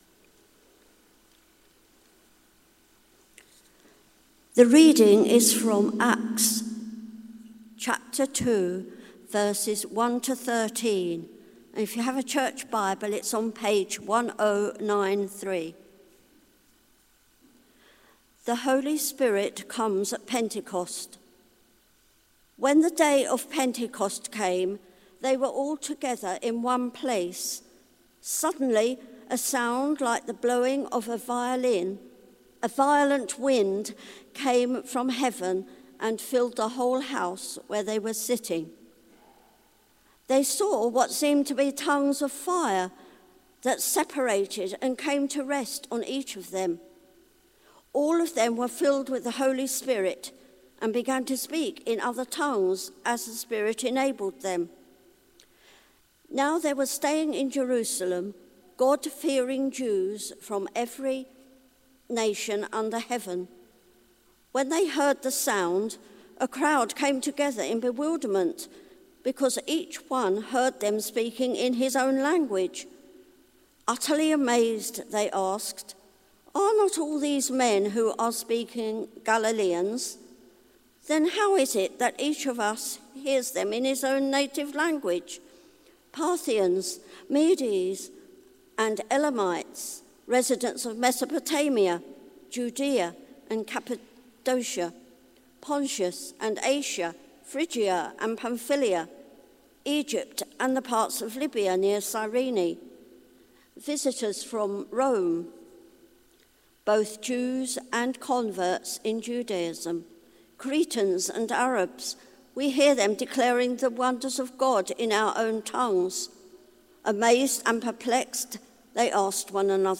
Media for Service (10.45) on Sun 02nd May 2021 10:45